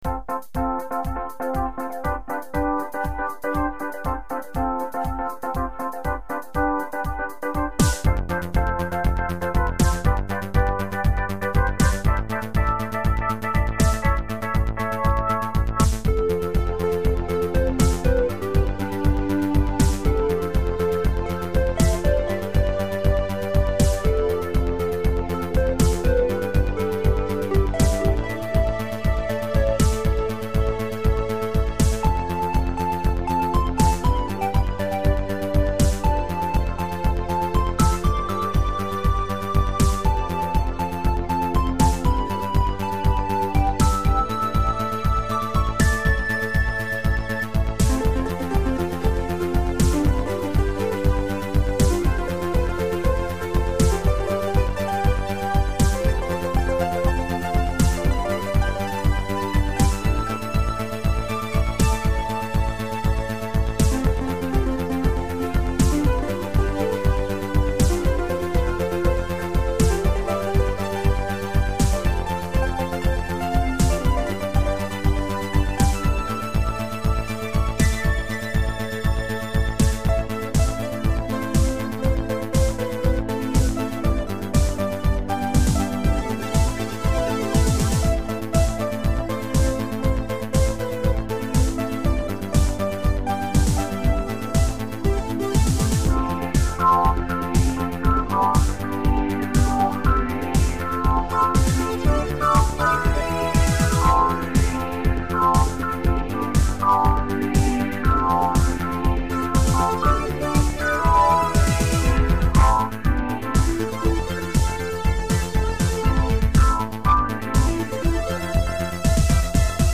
Démo MP3